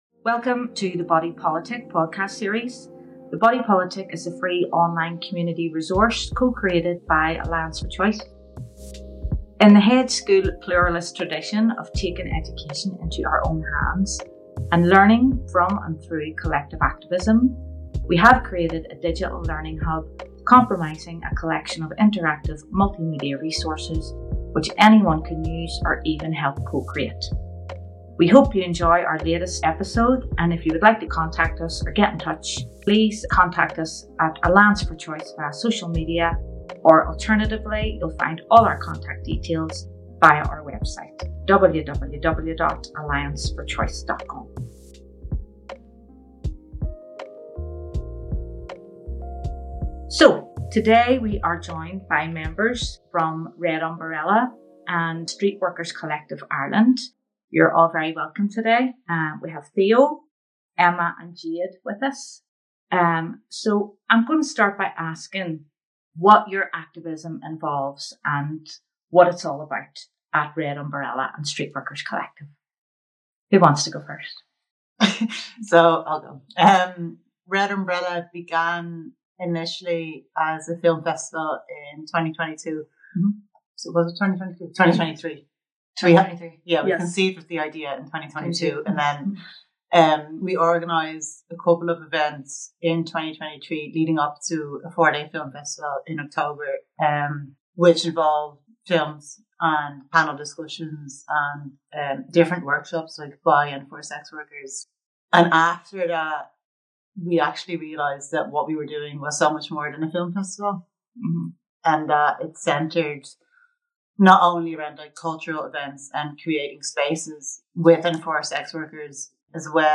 Intersections-and-Sex-Workers-Podcast-with-music.mp3